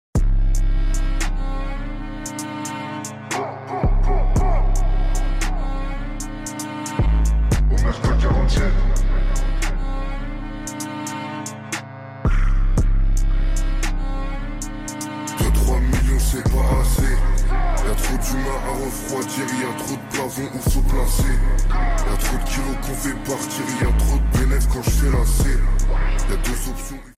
Here are free useful transitions sound effects free download